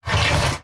emp-bridge-open.ogg